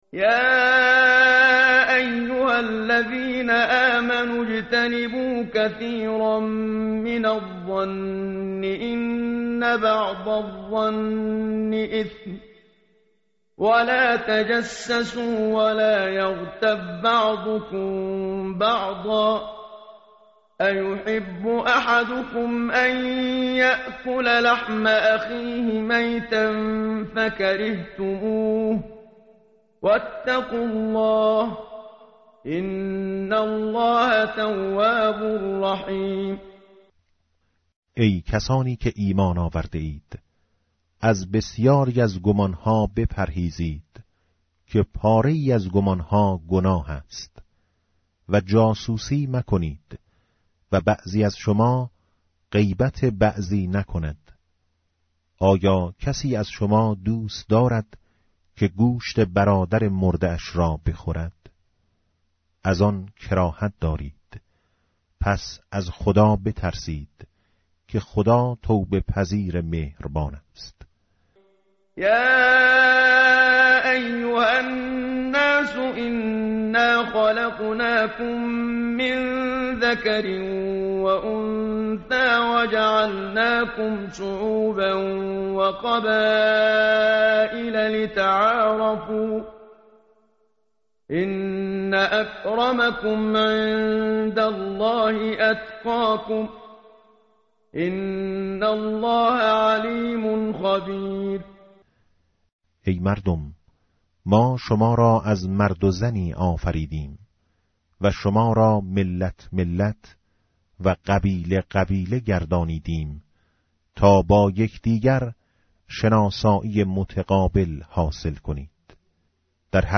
متن قرآن همراه باتلاوت قرآن و ترجمه
tartil_menshavi va tarjome_Page_517.mp3